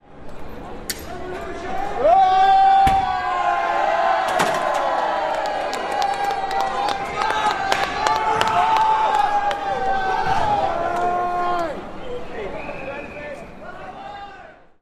Riot protest demonstration angry mob Aggression kit UK